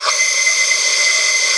Index of /90_sSampleCDs/Spectrasonics - Bizarre Guitar/Partition F/10 FEEDBACK